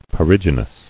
[pe·rig·y·nous]